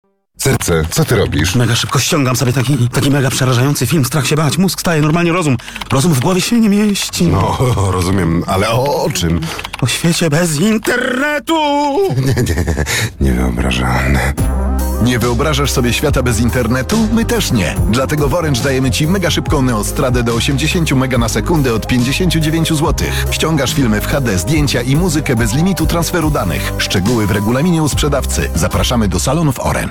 Sieć Orange ruszyła z dosyć zaskakującą radiową kampanią reklamową.
Nie wyobrażasz sobie świata bez Internetu? My też nie - radośnie zapewnia lektor w nowej reklamie Orange.